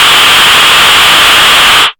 RADIOFX  7-R.wav